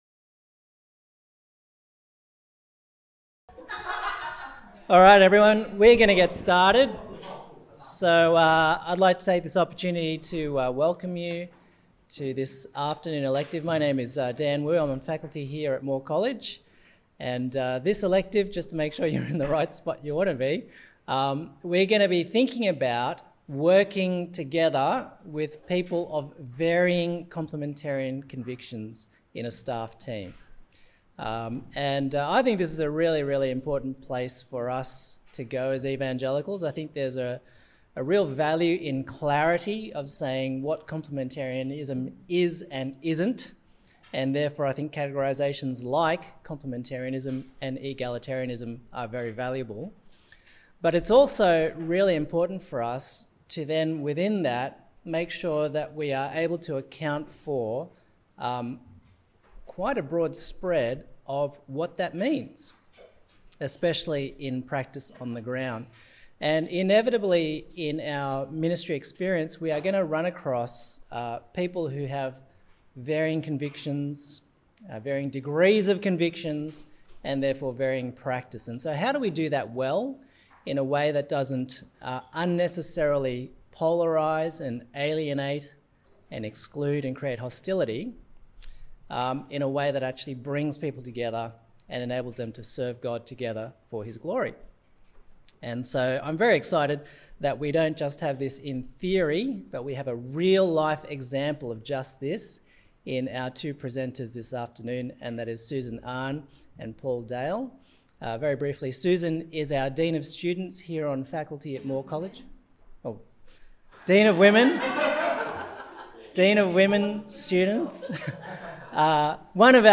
Elective presented at the 2024 Priscilla & Aquila Centre conference.